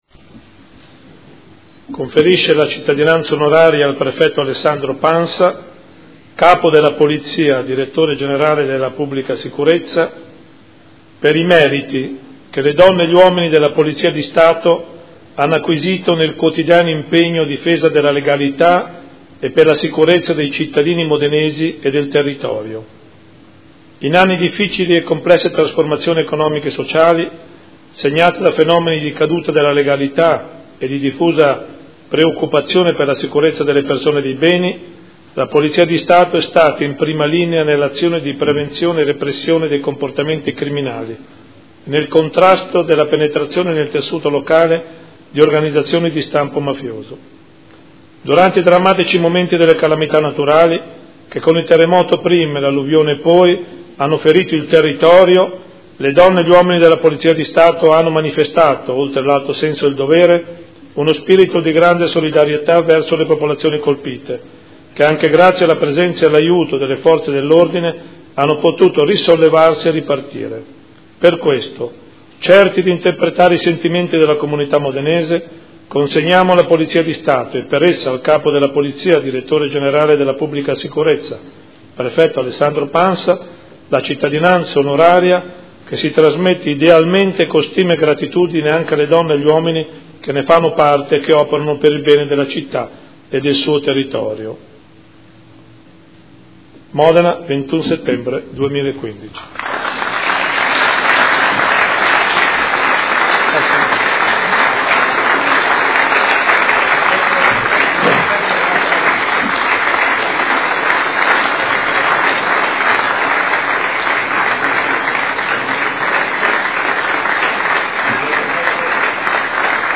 Seduta del 21/09/2015.
Il Sindaco legge le motivazioni della delibera